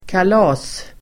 Uttal: [kal'a:s]